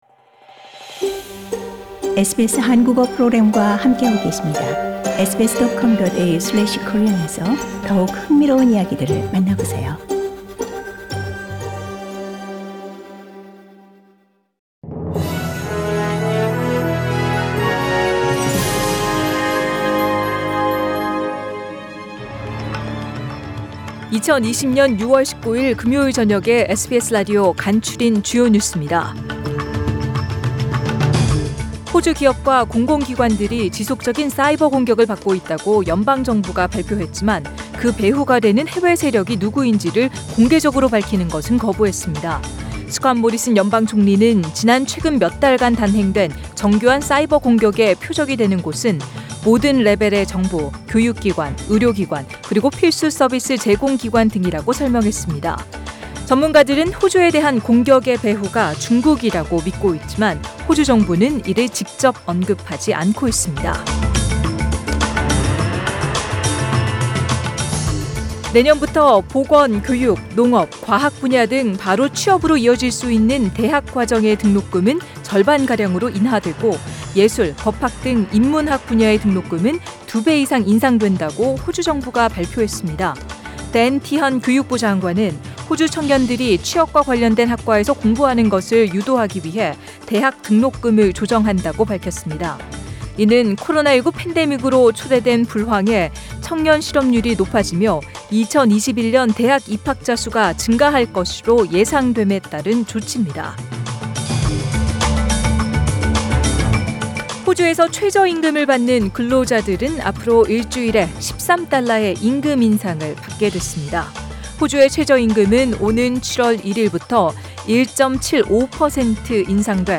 2020년 6월 19일 금요일 저녁의 SBS Radio 한국어 뉴스 간추린 주요 소식을 팟 캐스트를 통해 접하시기 바랍니다.